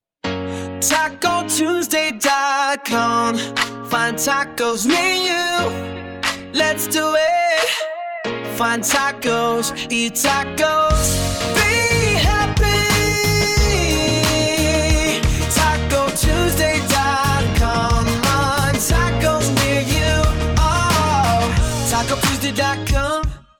Taco Tuesday R&B SONG dot com.mp3